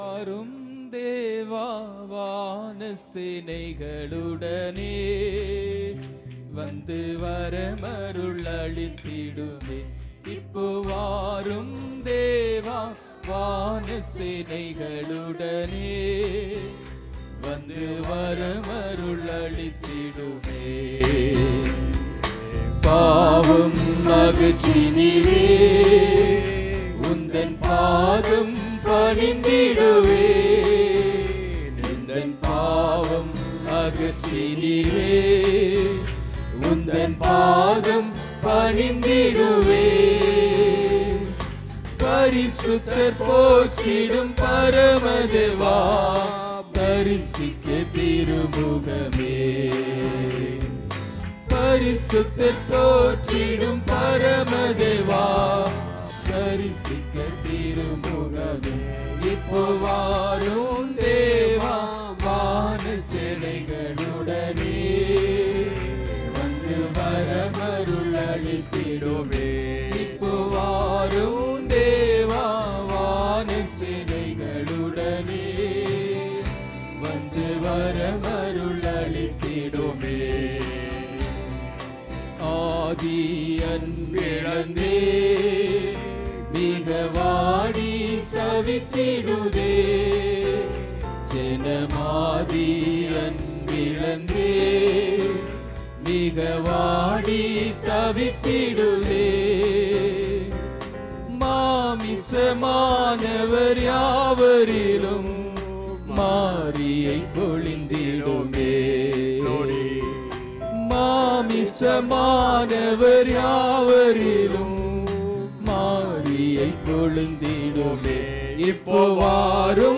12 Oct 2025 Sunday Morning Service – Christ King Faith Mission